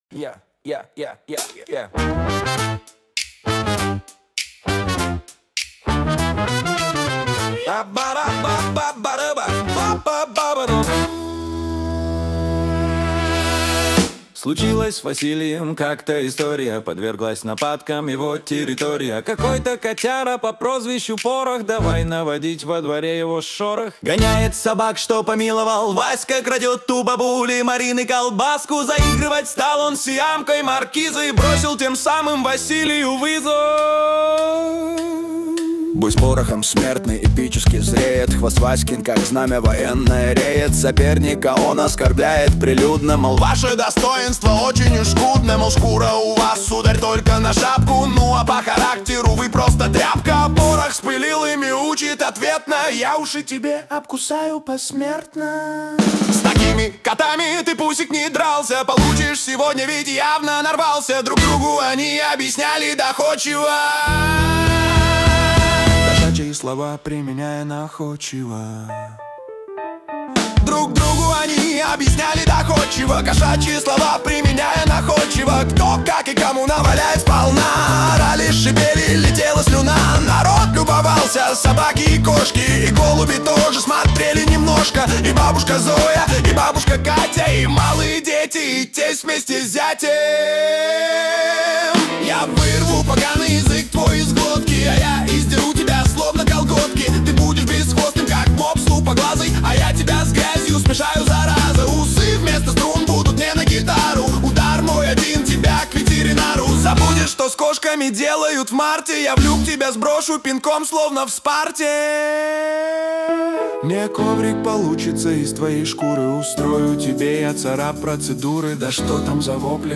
песню